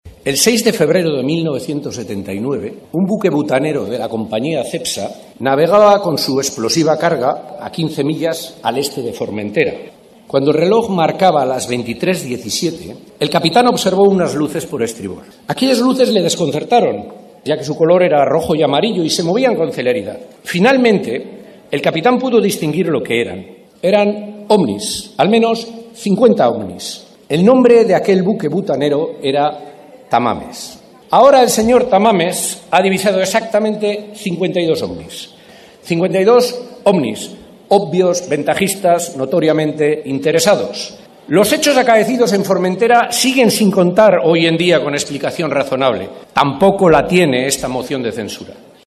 Aitor Esteban durant la seva intervenció al Congrés dels Diputats.
Així i tot, aquí teniu aquest extracte de la intervenció de n’Aitor Esteban i de la sorprenent relació entre Tamames i la nostra illa.